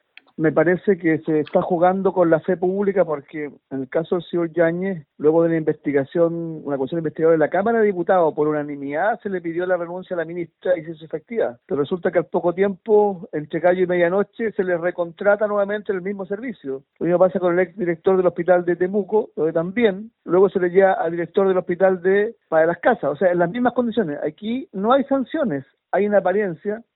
El diputado de la UDI y exintegrante de la Comisión Investigadora de listas de espera, Henry Leal, señaló que con estos hechos se está jugando con la fe pública.
cu-henry-leal.mp3